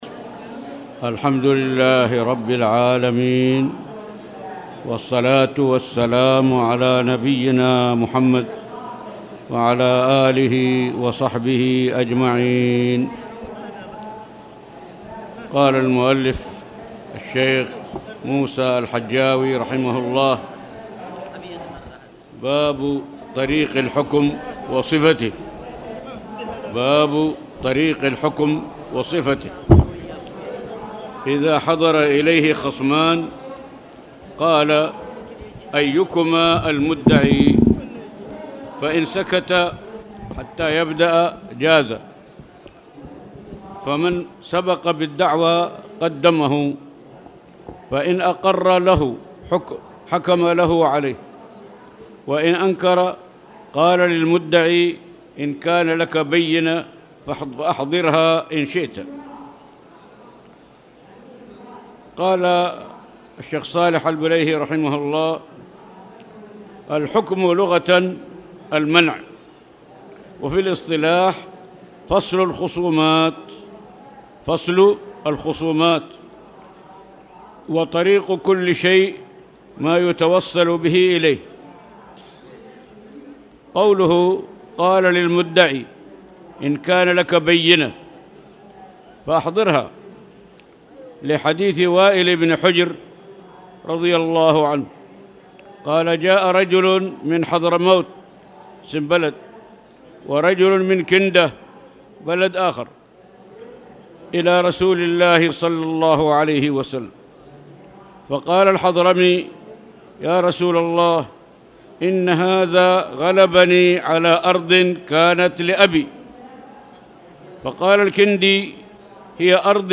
تاريخ النشر ٢١ ربيع الأول ١٤٣٨ هـ المكان: المسجد الحرام الشيخ